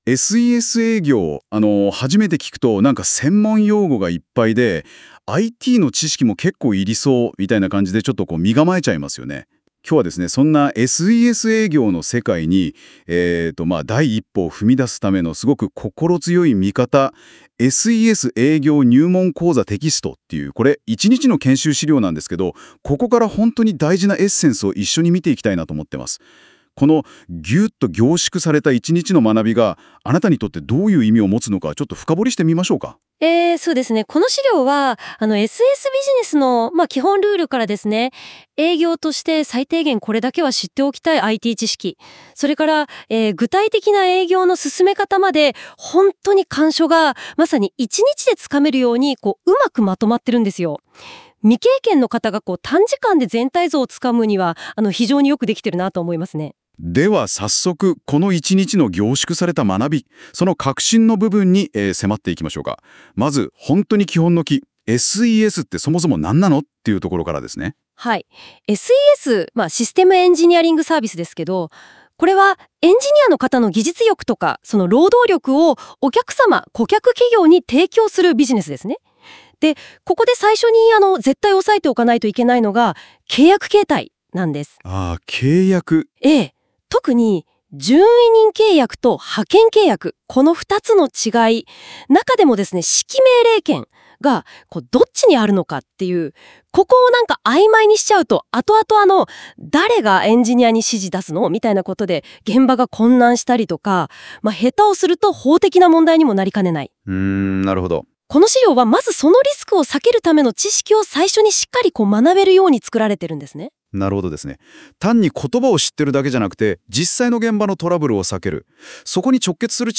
生成AI 研修PRトーク　※音声がでます